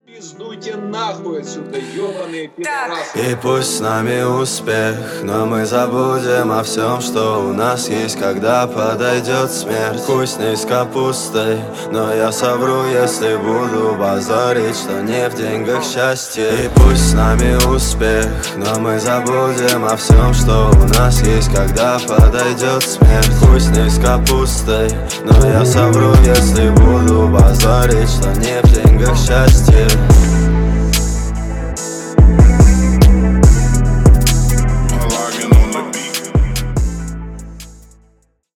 спокойные
русский рэп мужской голос качающие